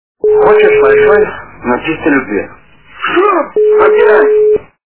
При прослушивании Хочешь большой и чистой любви - Шо, опять... качество понижено и присутствуют гудки.